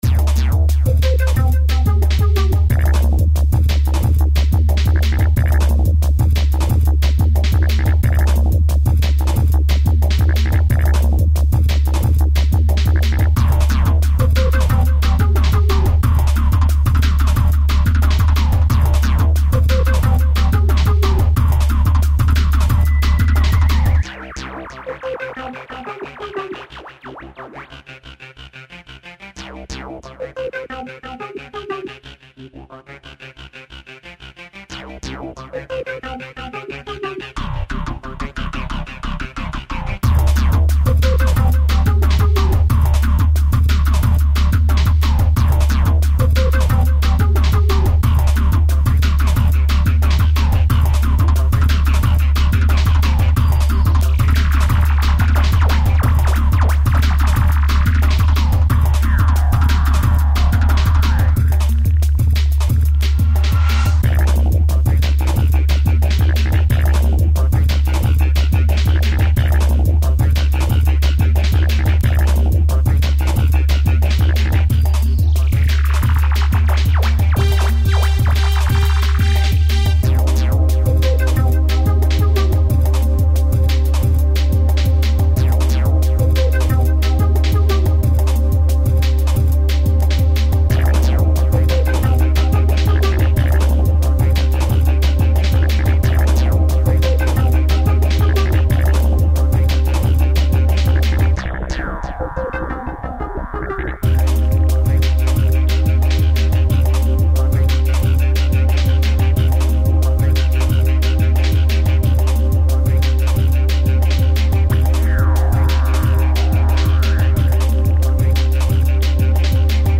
• Жанр: Танцевальная
Несмолкающие ломаные ритмы